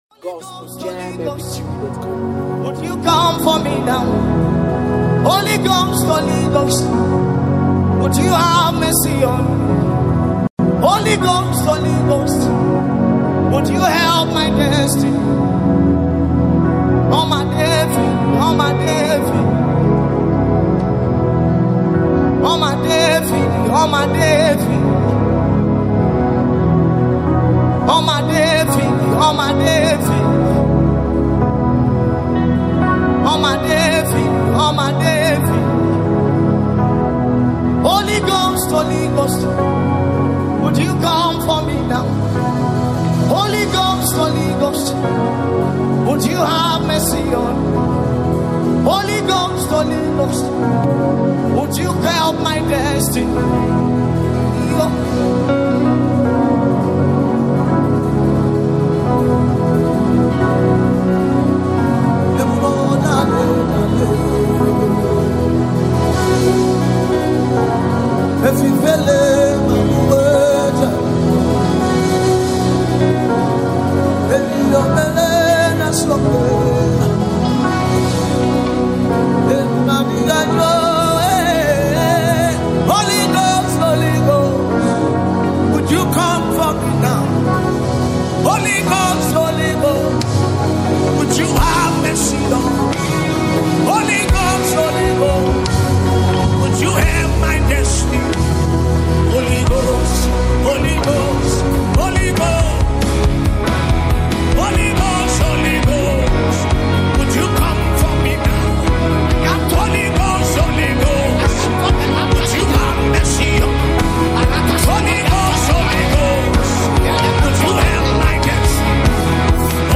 a sound of deep yearning
this worship anthem carries an atmosphere of revival